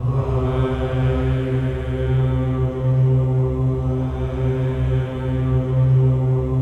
VOWEL MV01-R.wav